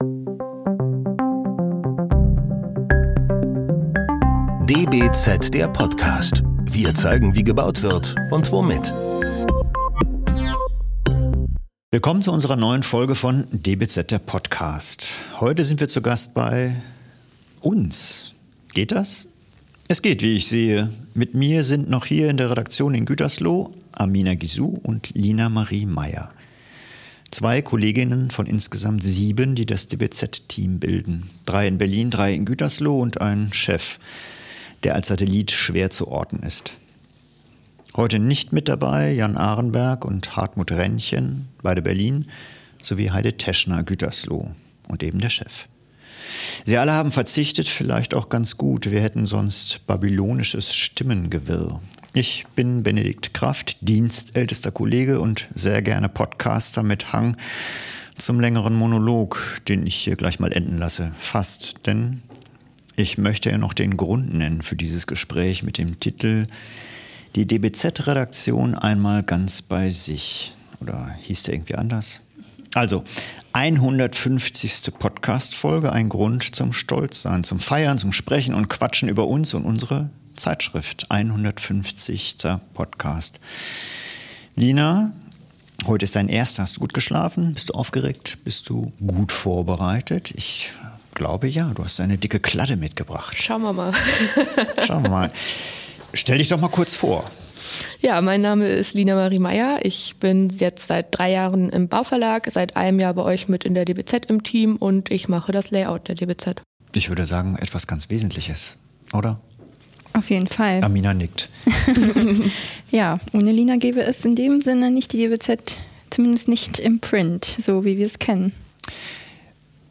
In der 150. Podcast-Folge sprechen wir über das Planen und Bauen, Menschen und Häuser, geben aber vor allem einen Einblick in unsere tägliche Arbeit. Im Selbstgespräch diskutiert die DBZ-Redaktion über denkwürdige Interviews, gute Eisbrecher, gelungenes Layout sowie ... einiges mehr.